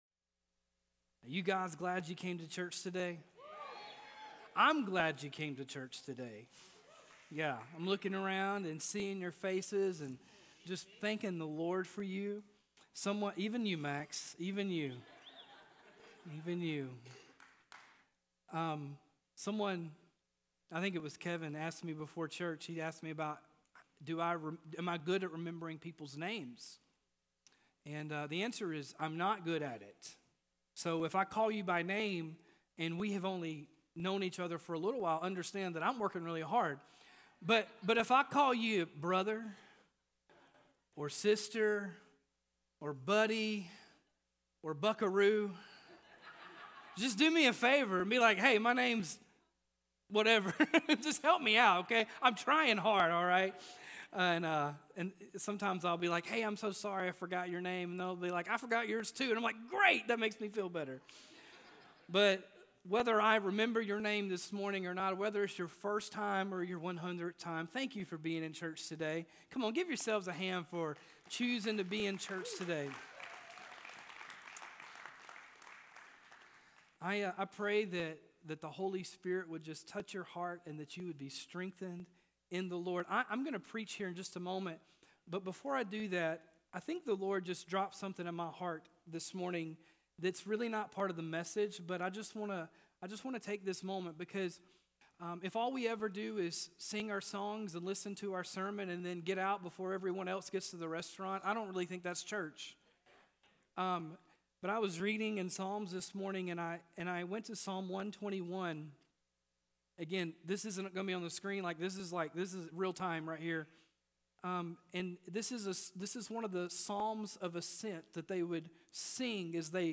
Join us for the last message in our "Carols" series on the song, "O Holy Night". This holy night was the most unique moment which led to the most horrific moment and then eventually the most beautiful moment in all of eternity.